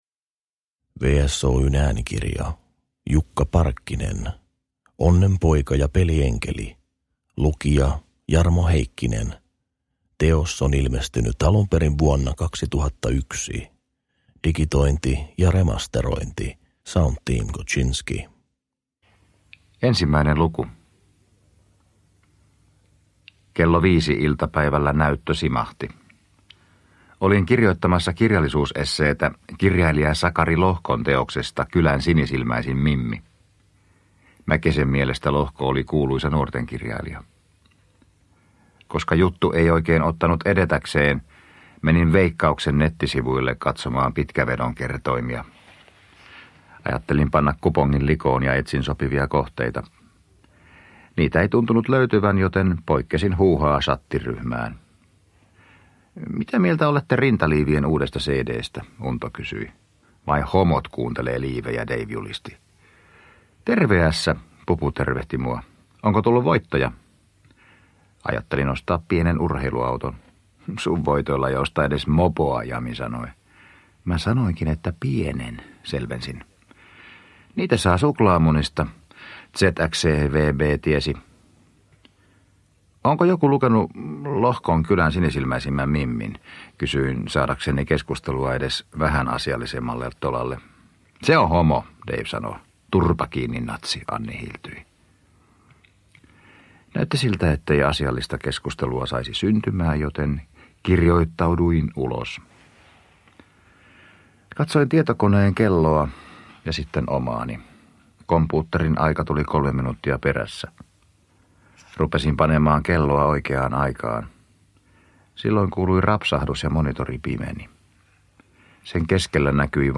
Onnenpoika ja pelienkeli – Ljudbok – Laddas ner